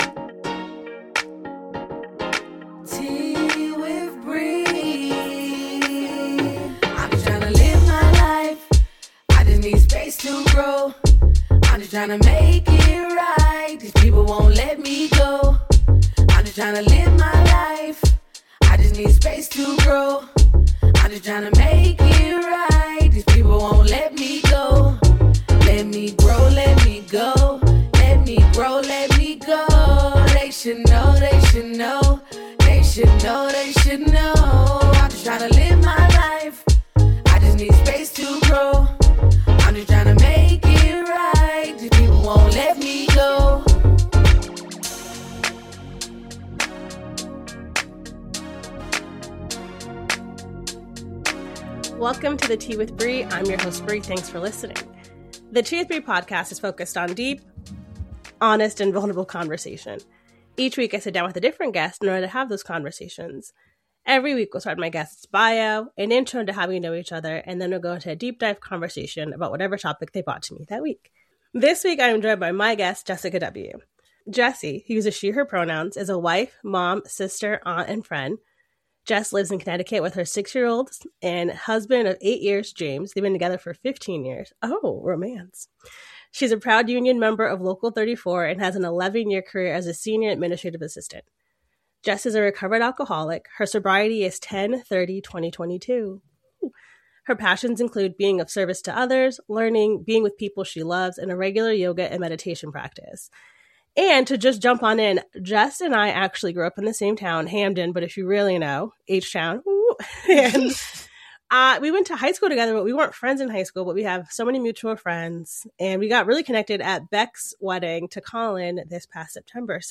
----- This podcast was recorded via Riverside FM.